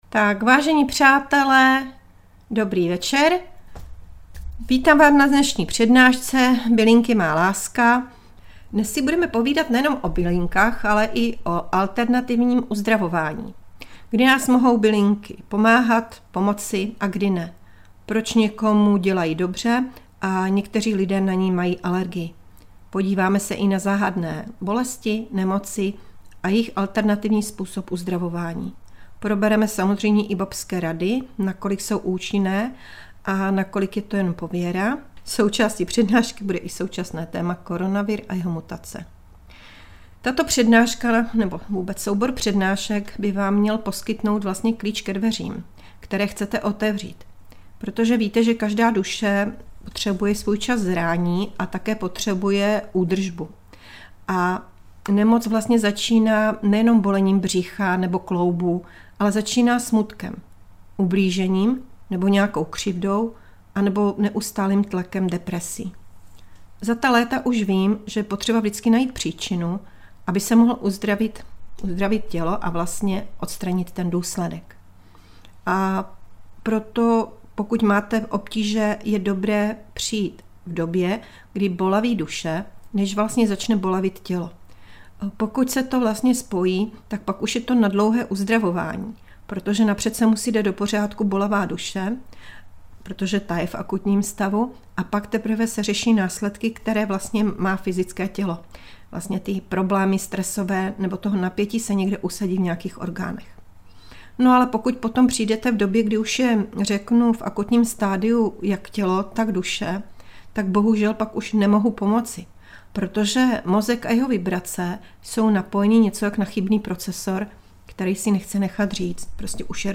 Na závěr Vám vždy formou malé meditace pošlu světlo, aby jste se cítili klidně a v pohodě.